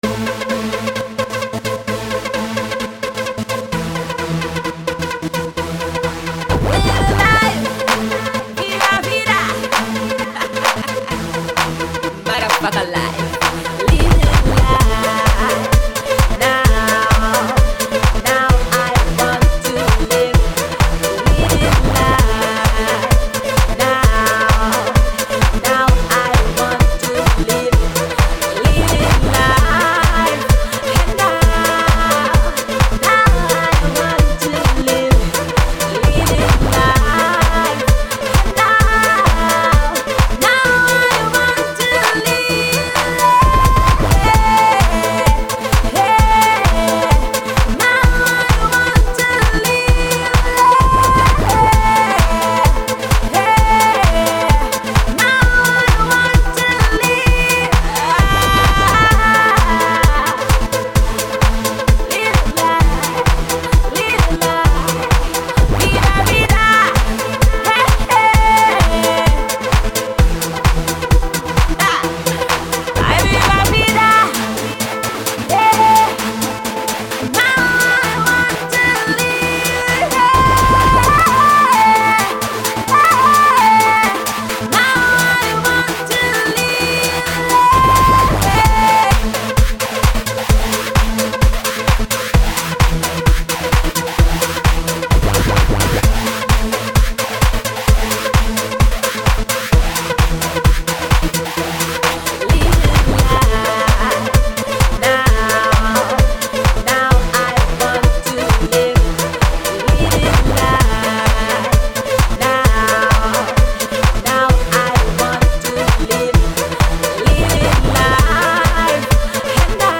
Pop, Kuduro Kuduro